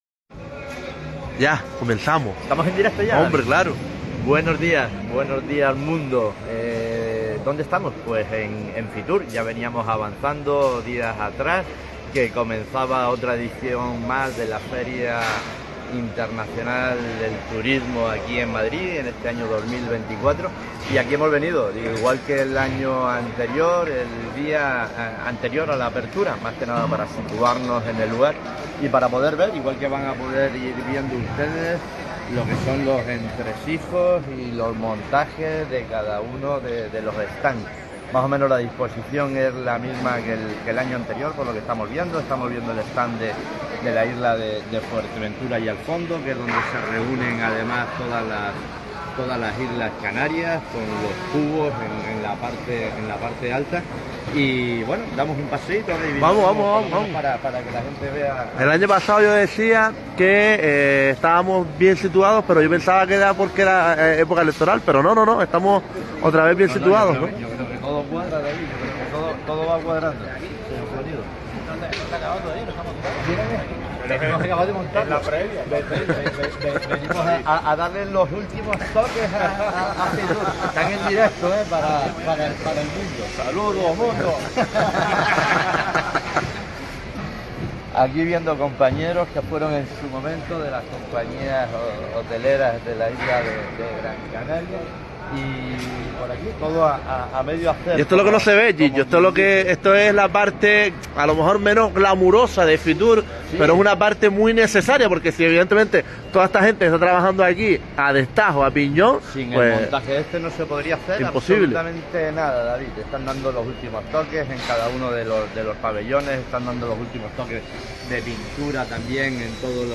Fitur 2024: Comenzamos a emitir desde el Pabellón 9 de IFEMA, donde está las Islas Canarias.